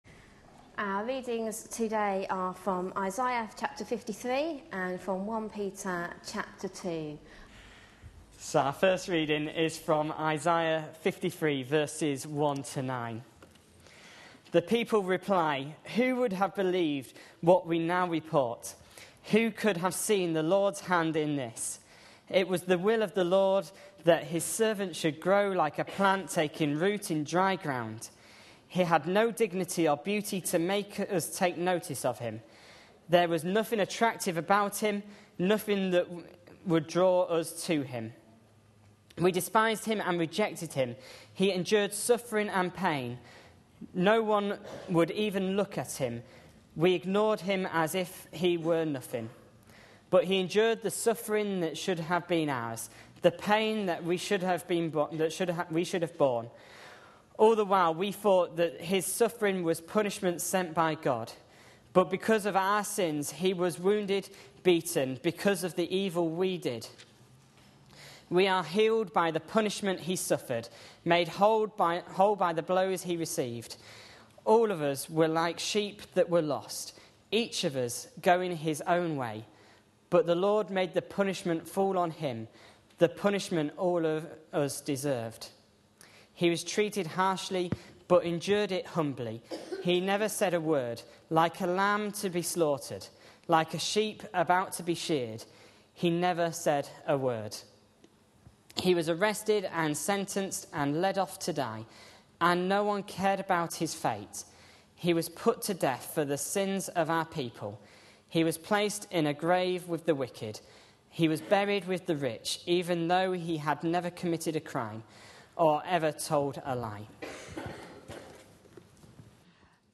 A sermon preached on 11th November, 2012, as part of our The Message of Peter for Today series.
The service was held on Remembrance Sunday.